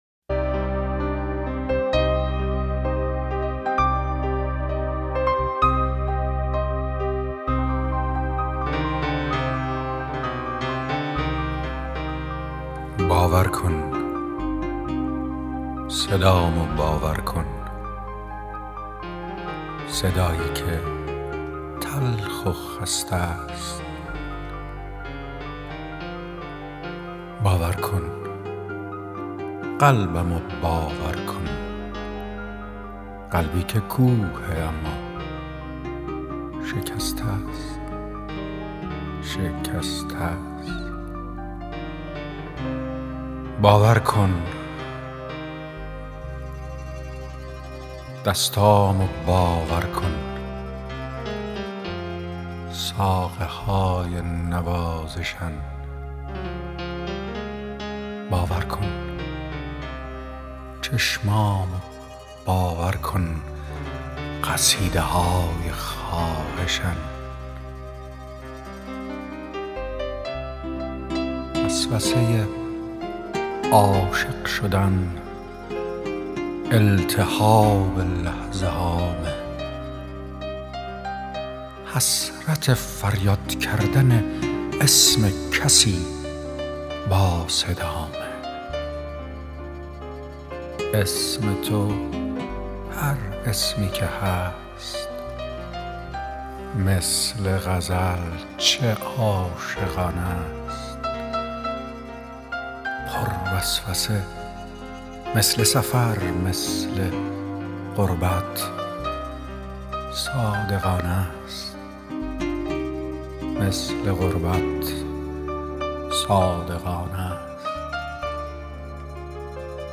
دانلود دکلمه باور کن با صدای ایرج جنتی عطایی با متن دکلمه
گوینده :   [ایرج جنتی عطایی]